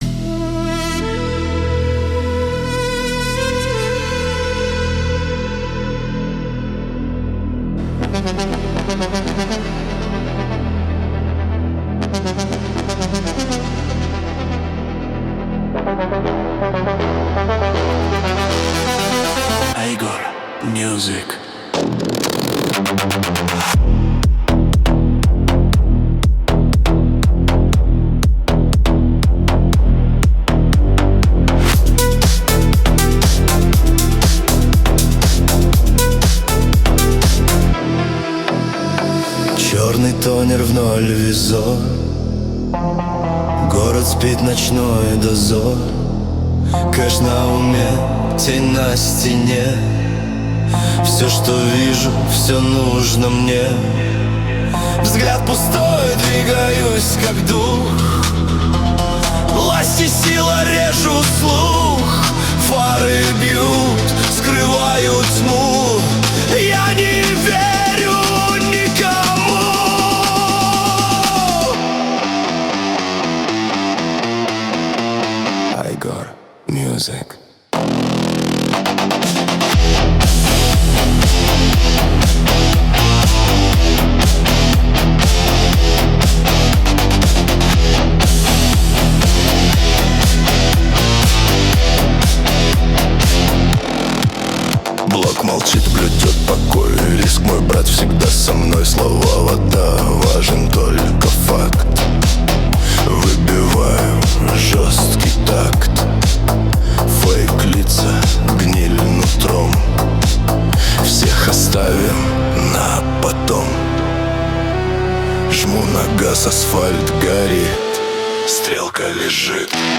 17 декабрь 2025 Русская AI музыка 118 прослушиваний